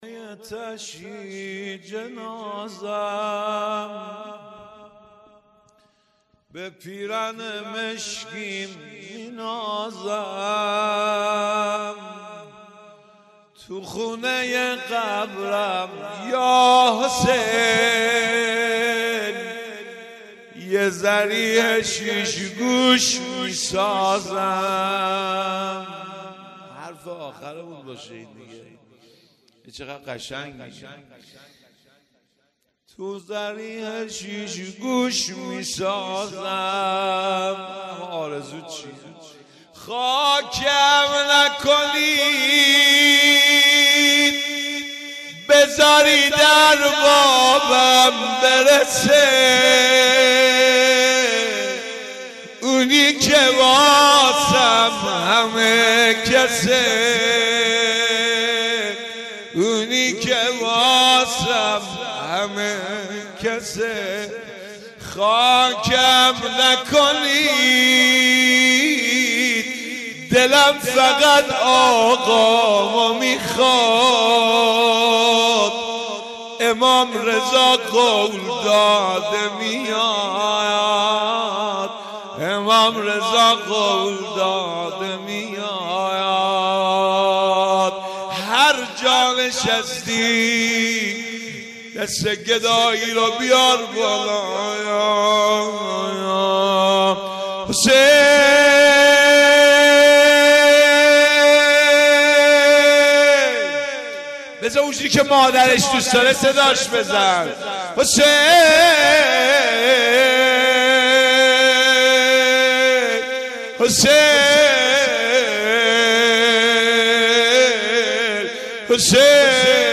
مناجات پایانی شب اول
شب های قدر 1401